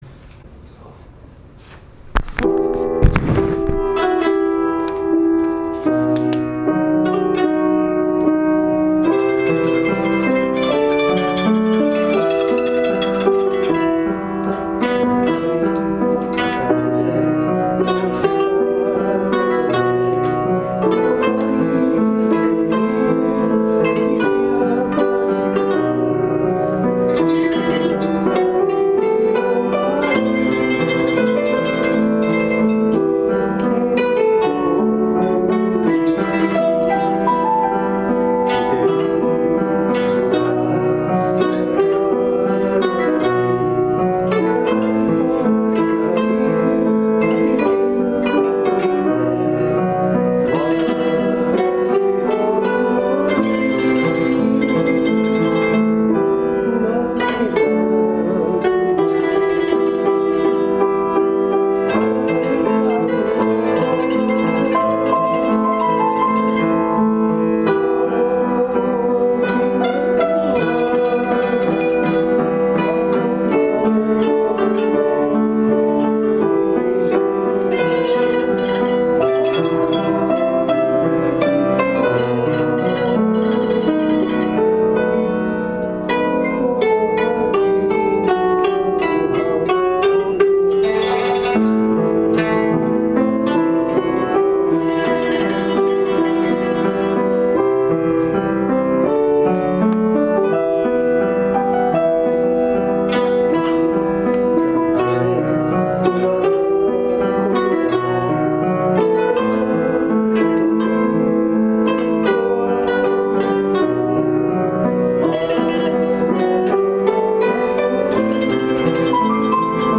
早春邦楽コンサート　無事終了しました。
、会場のみなさんにも一緒に歌って楽しんでいただく企画でした。